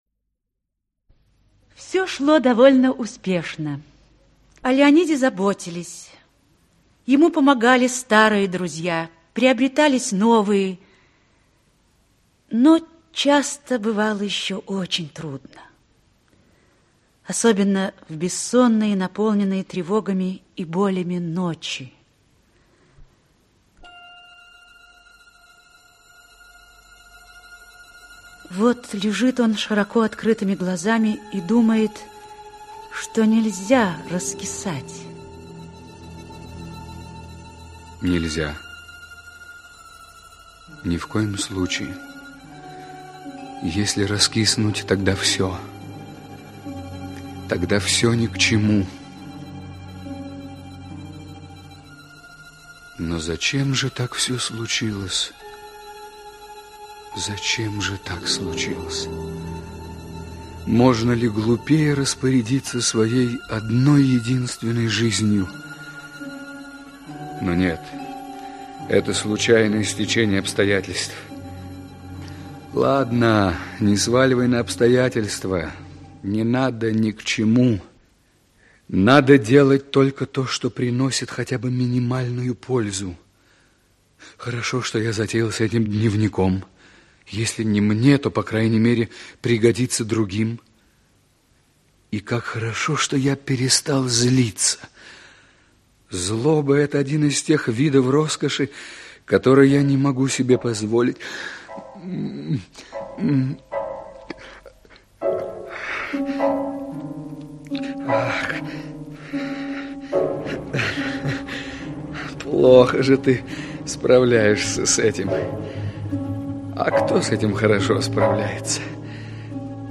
Аудиокнига Канат альпинистов. Часть 2 | Библиотека аудиокниг
Часть 2 Автор Давид Медведенко Читает аудиокнигу Актерский коллектив.